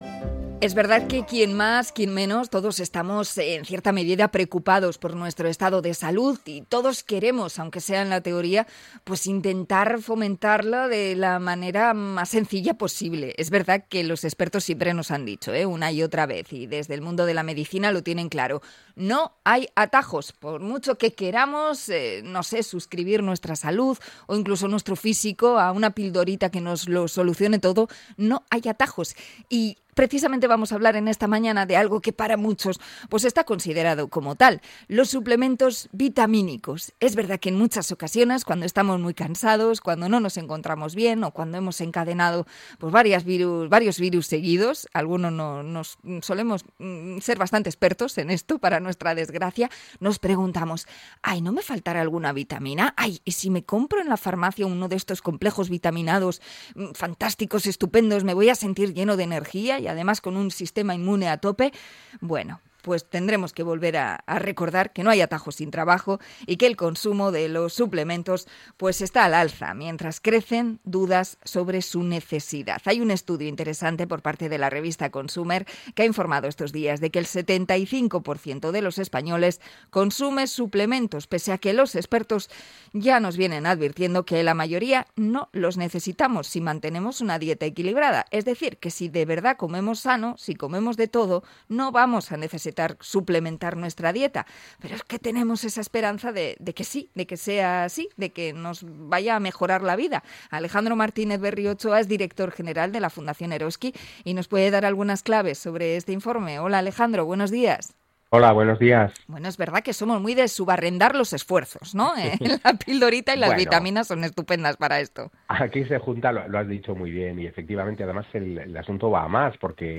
Entrevista sobre los suplementos nutricionales